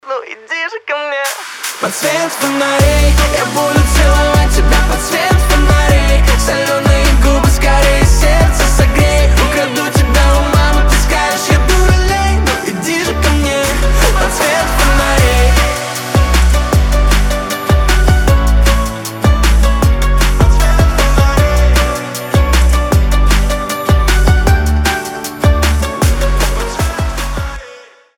• Качество: 320, Stereo
дуэт
легкие